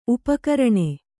♪ upakaraṇe